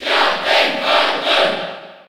Category:Crowd cheers (SSB4) You cannot overwrite this file.
Captain_Falcon_Cheer_French_PAL_SSB4.ogg